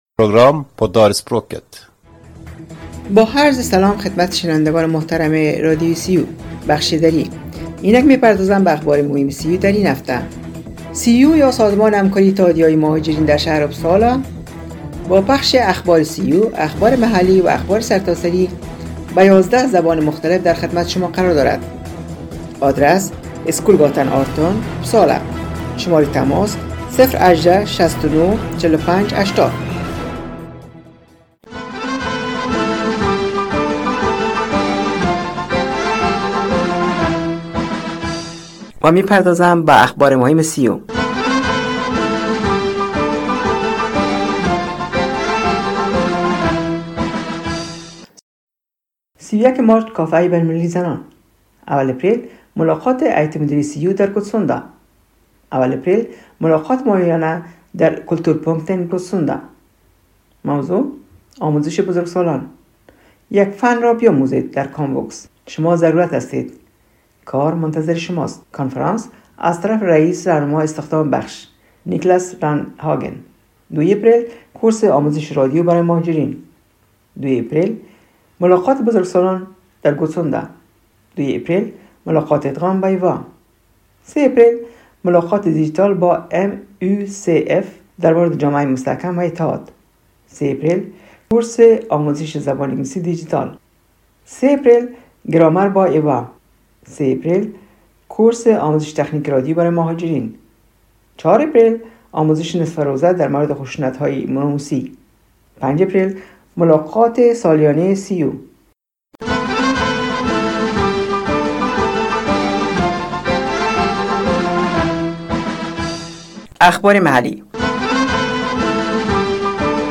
شنوندگان گرامی برنامه دری رادیو ریو یا انترنشنال رادیو در اپسالا سویدن روی موج ۹۸،۹ FM شنبه ها ازساعت ۸:۳۰ تا ۹ شب به وقت سویدن پخش میگردد که شامل اخبار سیو، اخبارمحلی و اخبارسرتاسری میباشد